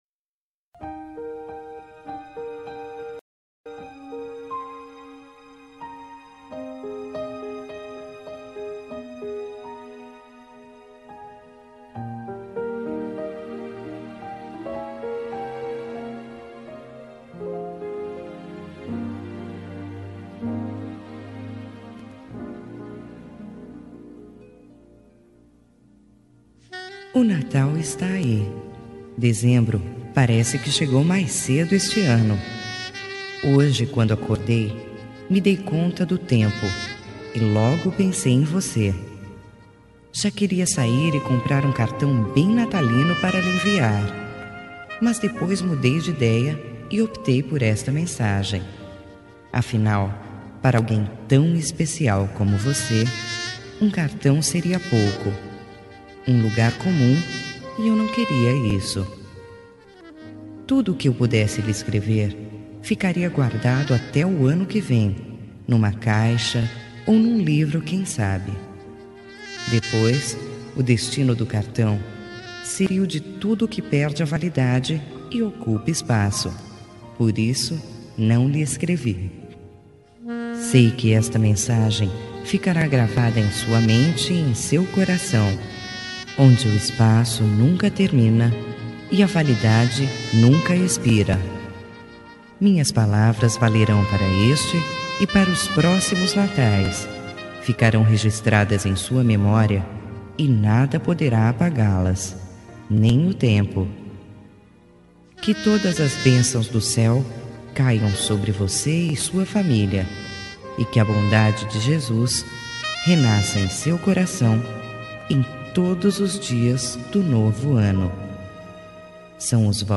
Natal Pessoa Especial – Voz Feminina – Cód: 348891